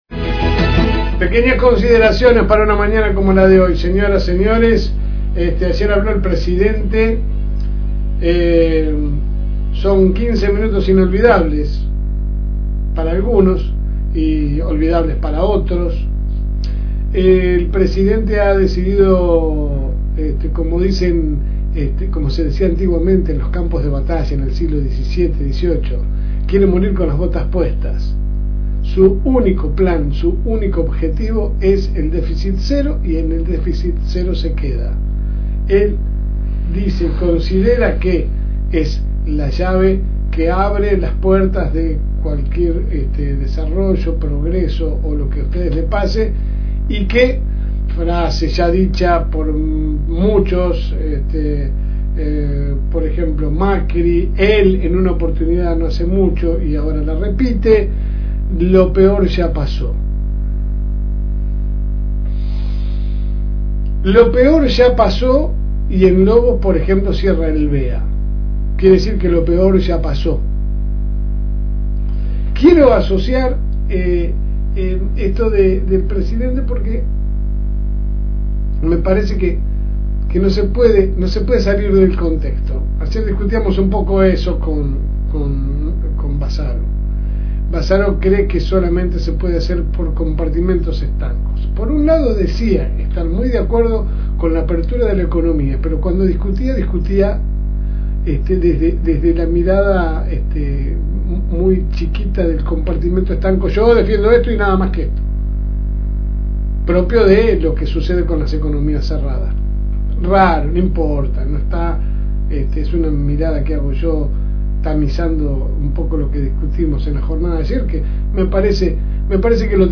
Que sale por el aire de la FM Reencuentro 102.9